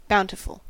Ääntäminen
IPA : /ˈbaʊntɪfʌl/